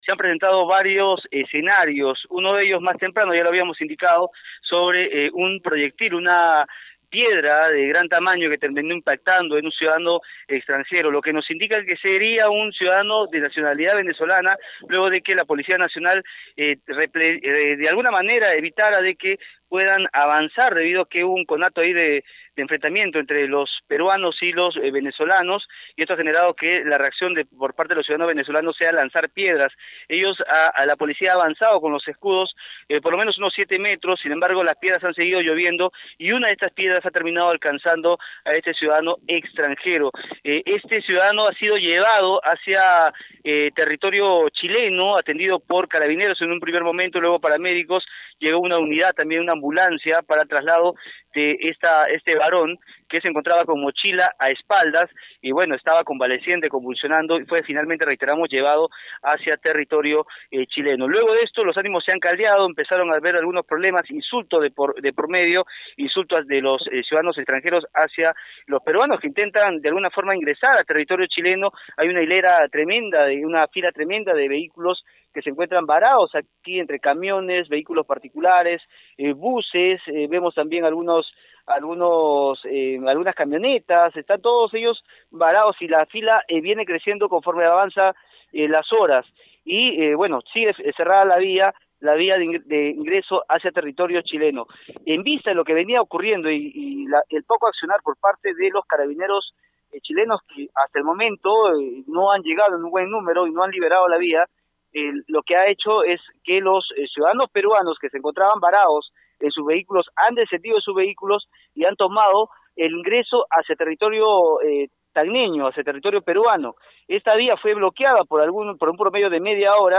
despacho-frontera-sur.mp3